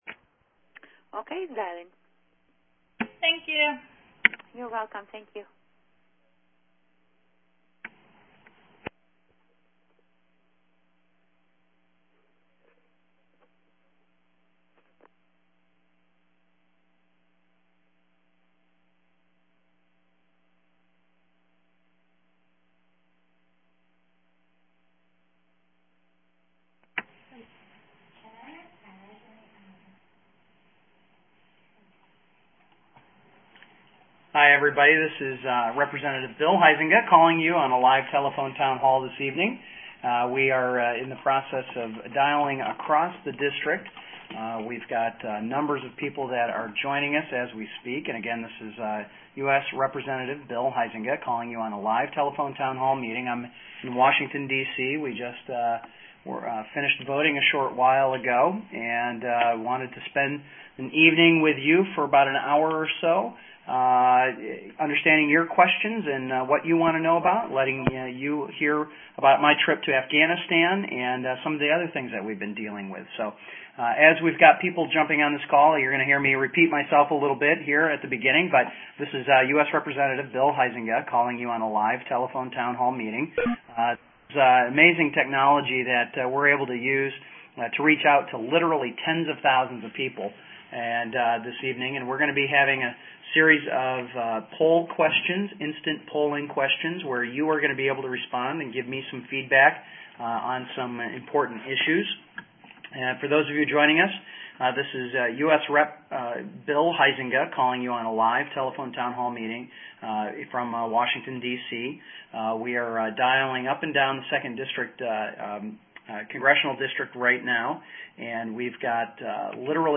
U.S. Rep. Bill Huizenga, MI-02, hosted a teletown hall on February 2, to hear from constituents across the 2nd District on current events in the district and D.C., including the Keystone Pipeline, what’s on the agenda for the year ahead in Congress, continued efforts to cut spending and promote job creation, the deficit and debt’s impact on the economy, his recent visit to thank the troops from Michigan in Afghanistan, as well as a number of other federal issues constituents wanted like to discuss.